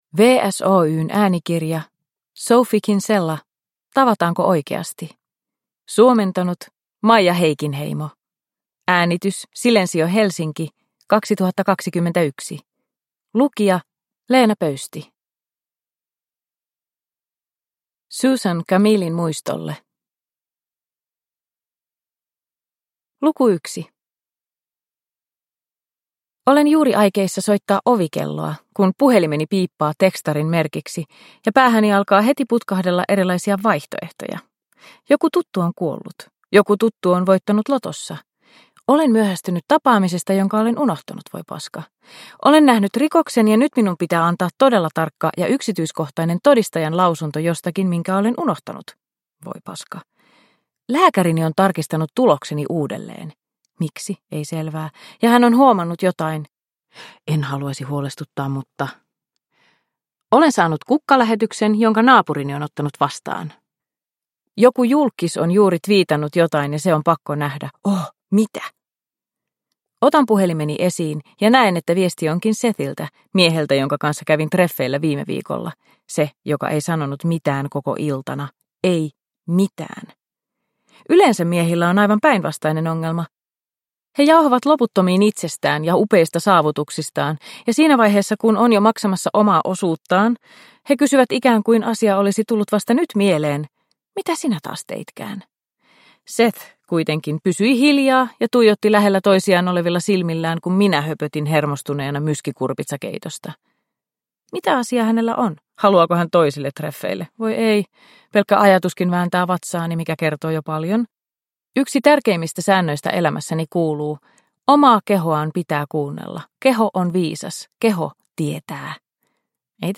Tavataanko oikeasti? – Ljudbok – Laddas ner